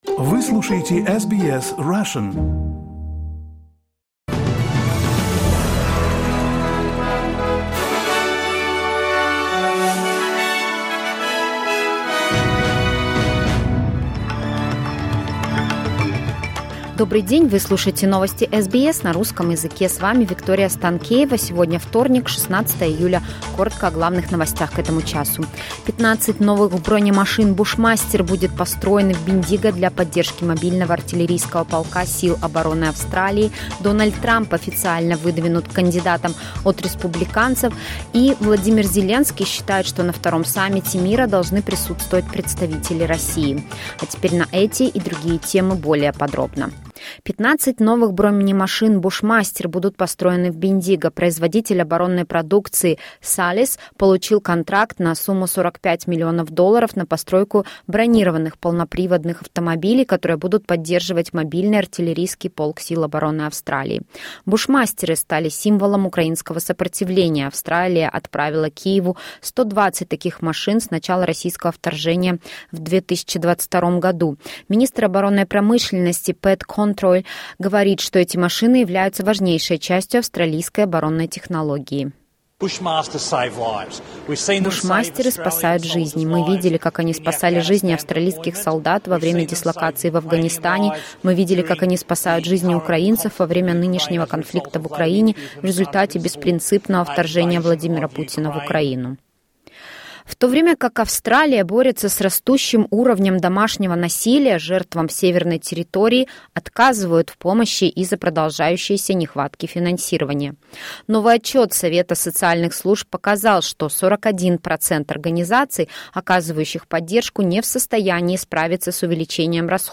Latest news headlines in Australia from SBS Russian